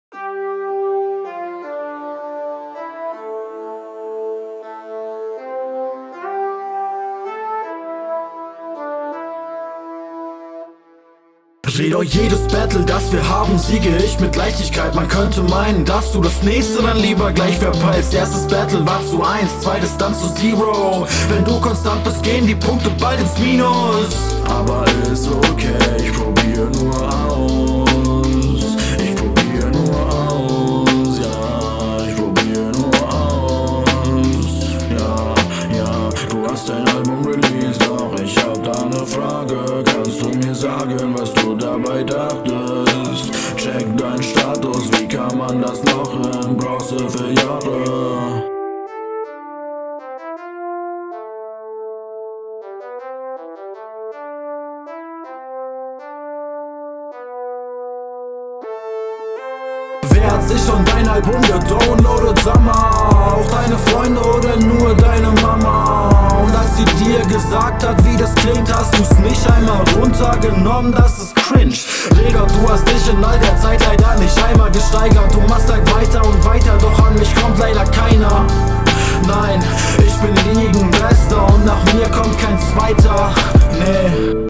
Bisschen zu viel Sing Sang, der Anfang ist aber killer und der Teil am Ende. …